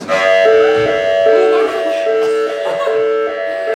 KÕRVULUKUSTAV SIREEN!